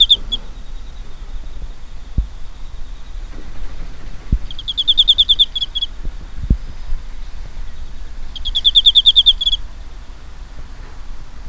コオロギの鳴き声
庭でコオロギが鳴いています。
koorogi.wav